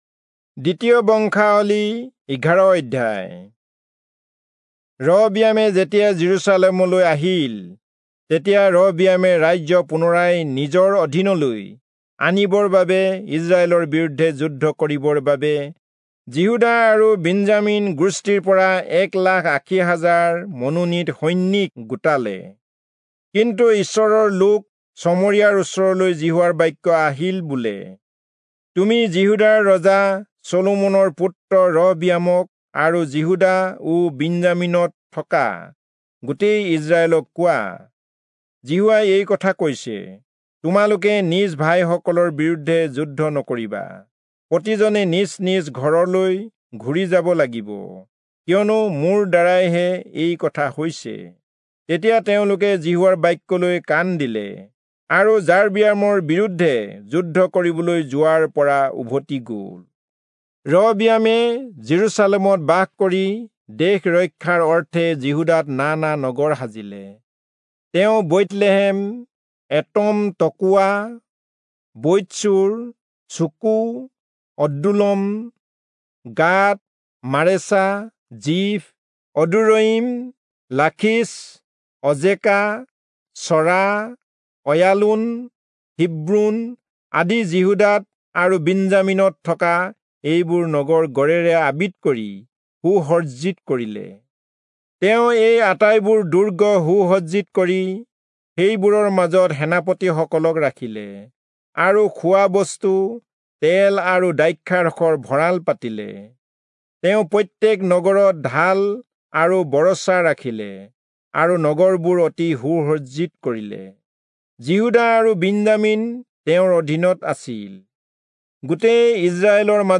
Assamese Audio Bible - 2-Chronicles 8 in Irvhi bible version